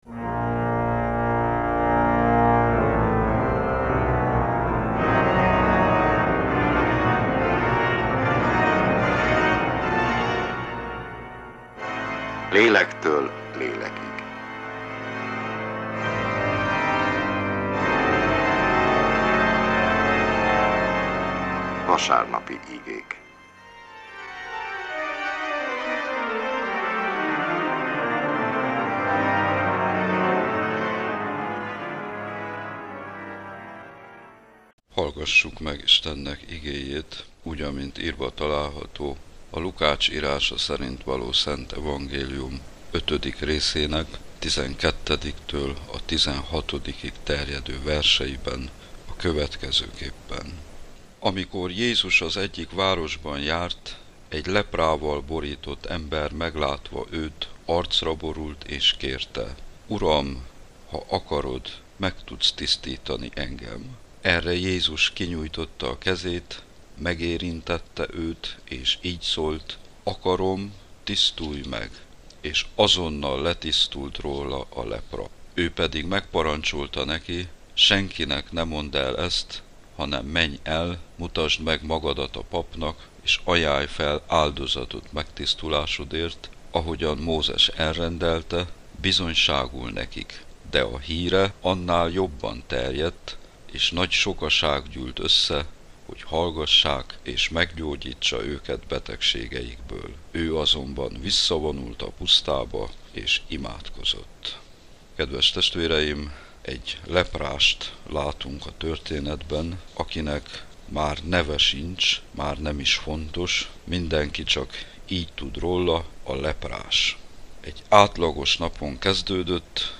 Református igehirdetés, január 31.
Egyházi műsor